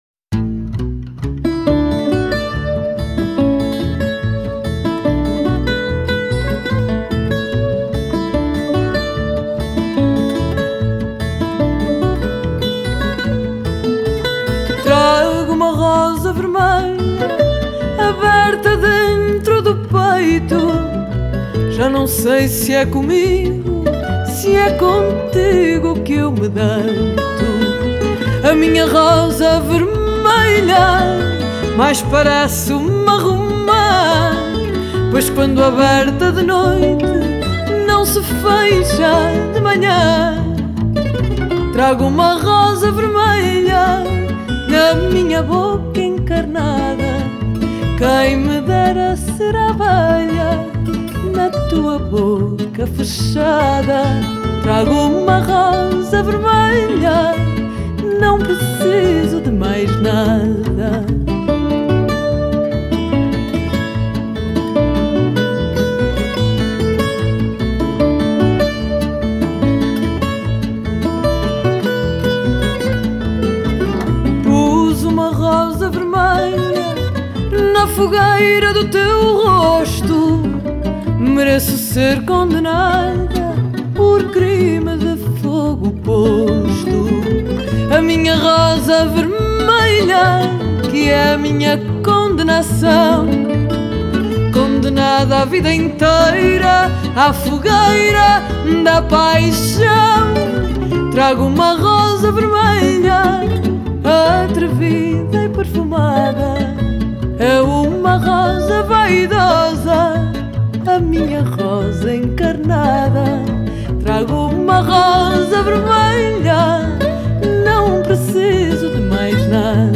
Genre: World, Fado